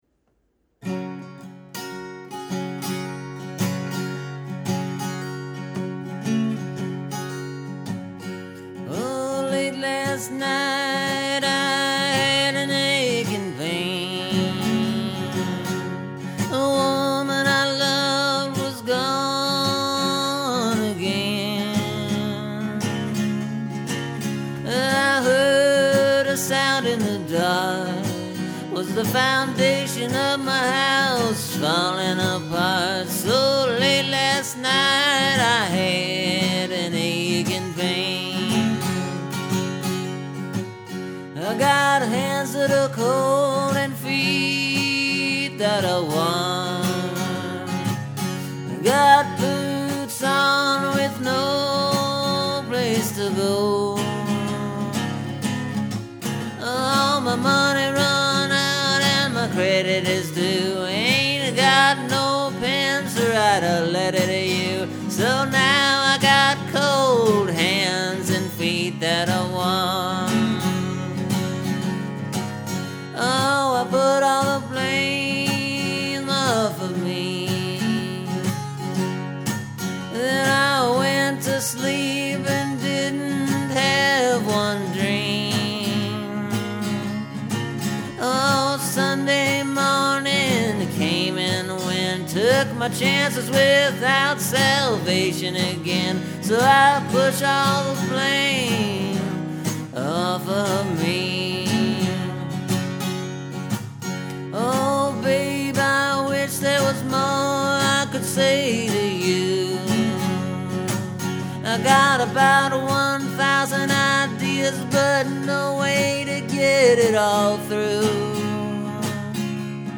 So here’s the new re-recording of “Some Ordinary Troubles.”
It’s still kind of a blues-type tune. (Although, it’s a little more folk-song-oriented, I’ll admit that.)